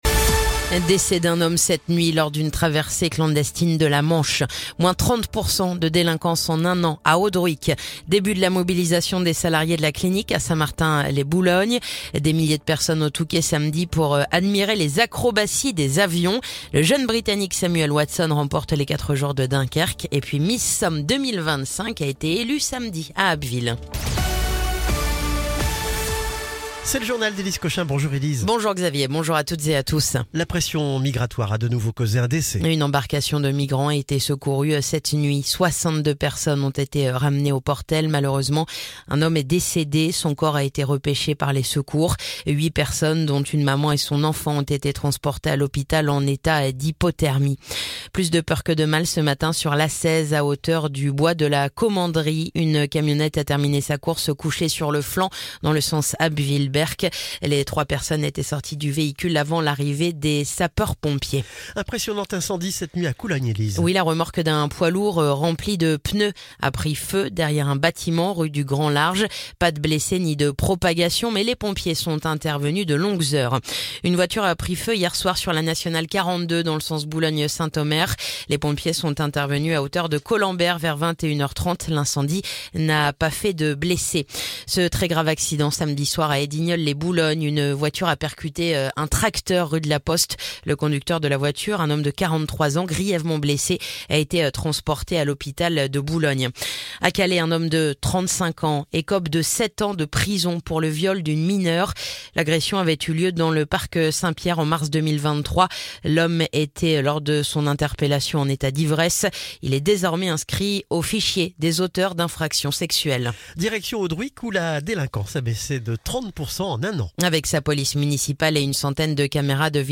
Le journal du lundi 19 mai